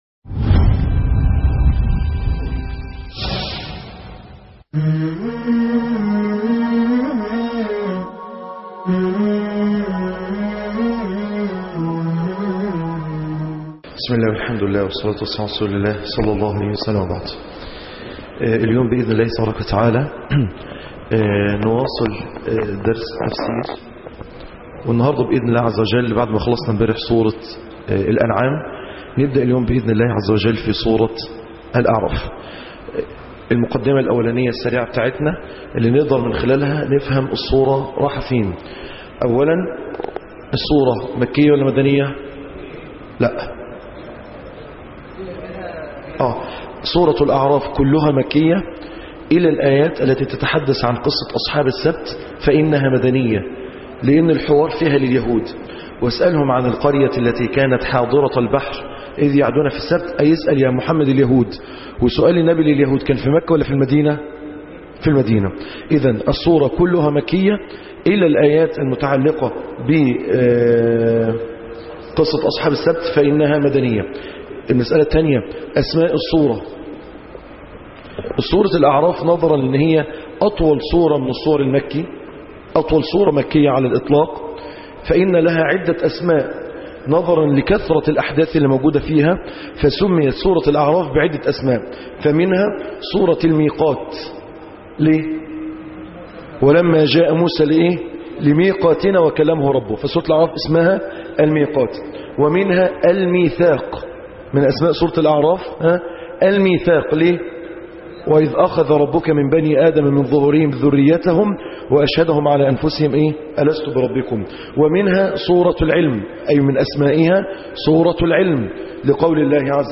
(8) الجزء الثامن-سورة الأعراف(5-7-2014)دروس تفسير القرءان(رمضان 1435